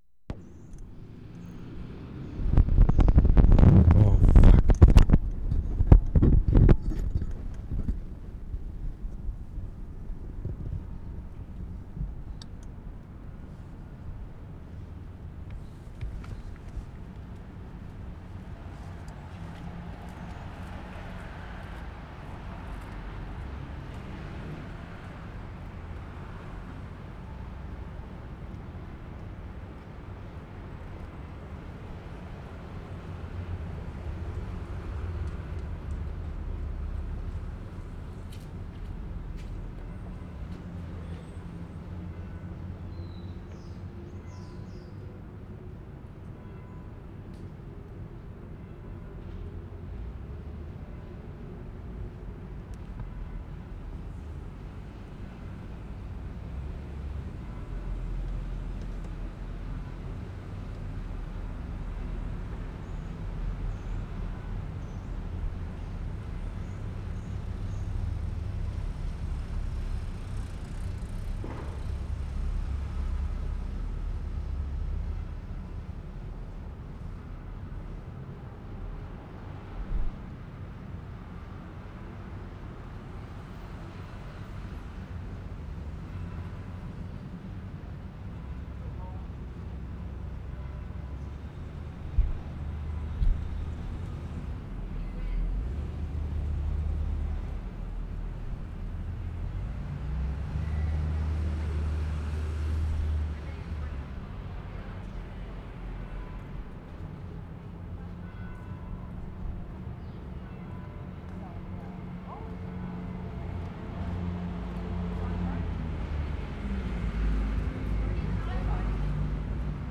ST. JOHN, NEW BRUNSWICK
AMBIENCE OUTSIDE TRINITY CHURCH 5'40"
Microphone disturbance for first 10". During first two minutes faint bell from downtown church, masked mostly by traffic. This is at 11 a.m. More bells, even fainter from downtown (3'30").